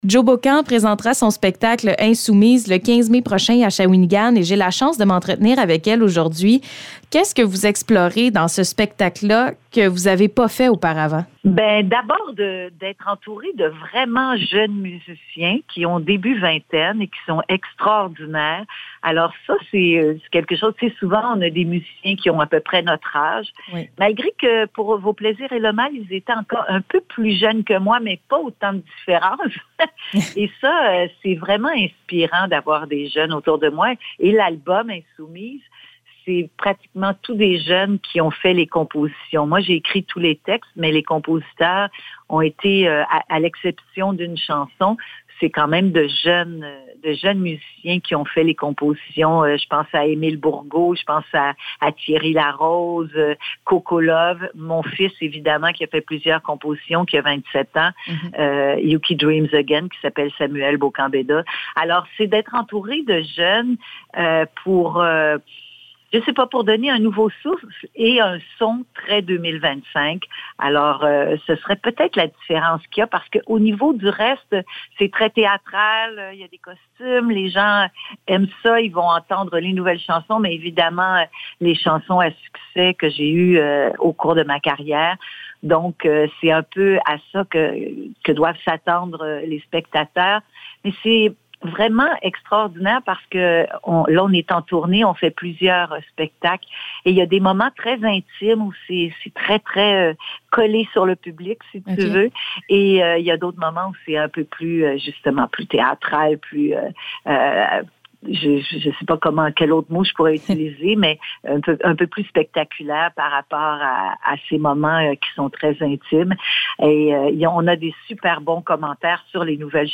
Entrevue avec Joe Bocan